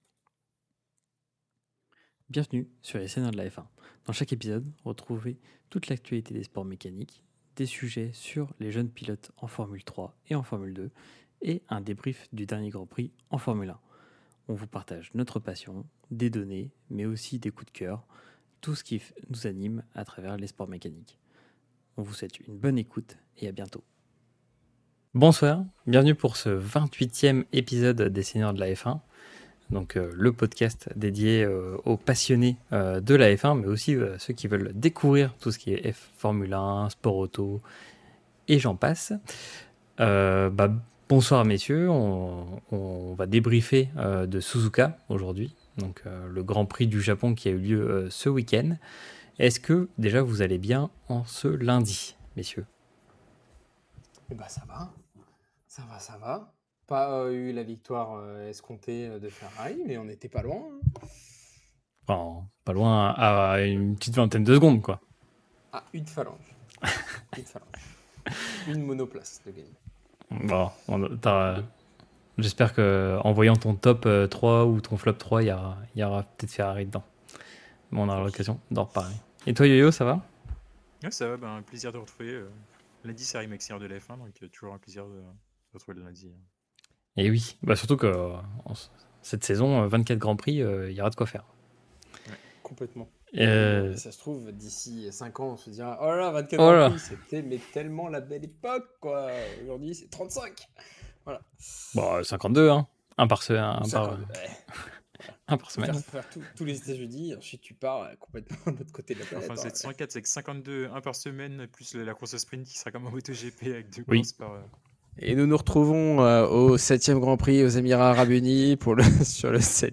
Retrouvez nous en live lors de l'enregistrement sur Twitch :